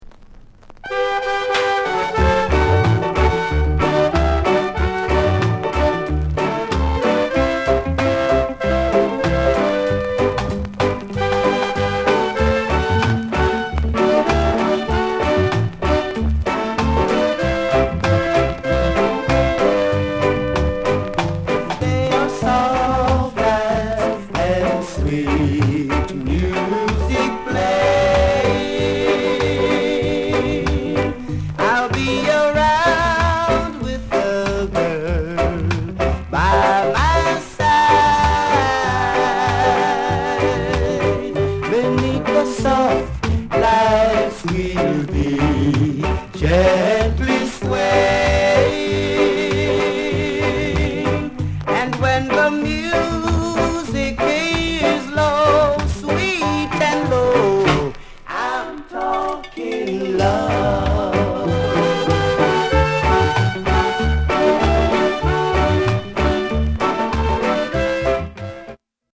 ROCKSTEADY